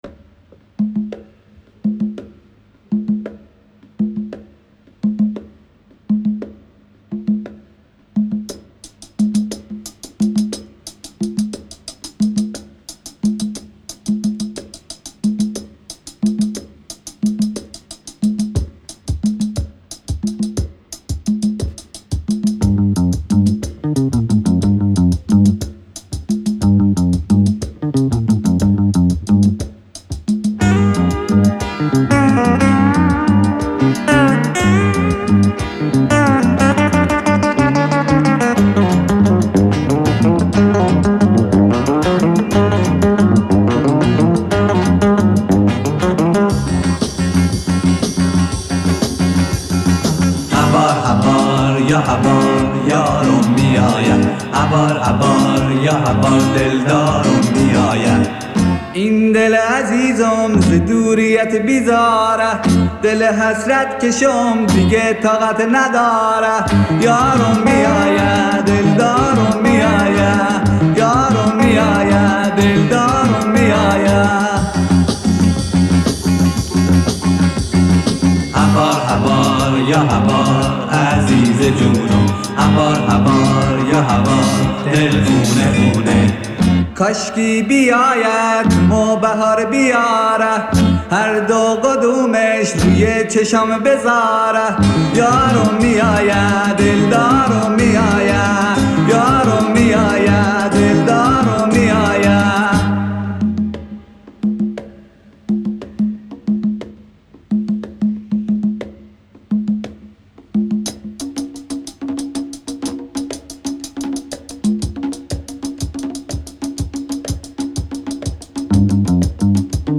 راک ایرانی